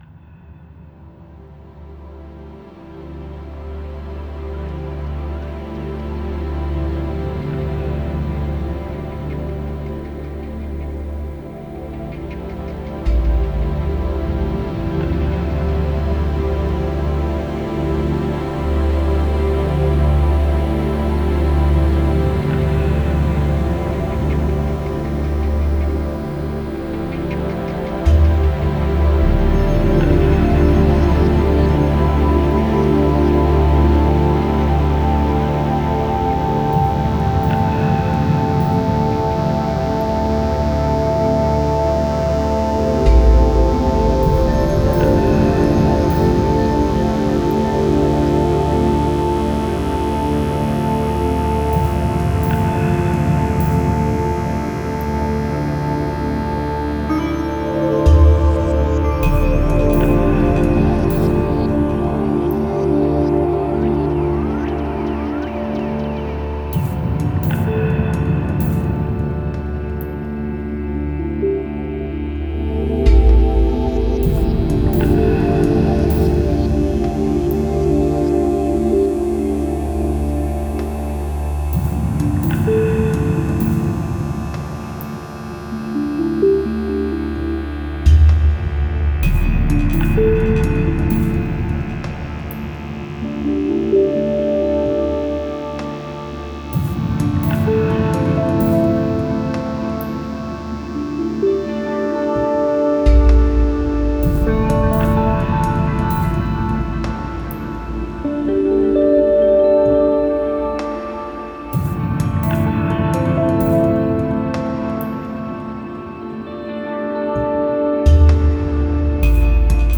Genre: Ambient, IDM, Chillout.